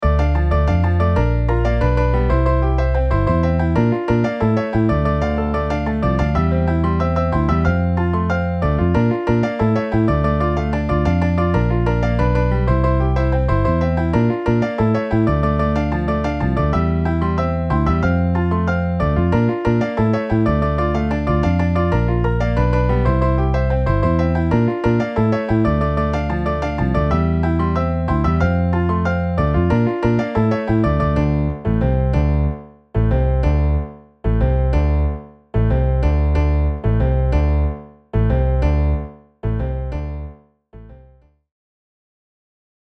Saxo Alto, 2X Trompetas, 2X Trombones, Piano, Bajo